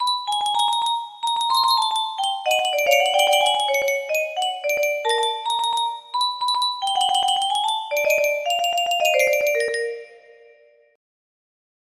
Rats' Day music box melody